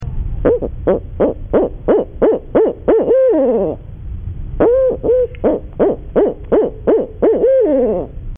Barred Owl-sound-HIingtone
barred-owl-hooting.mp3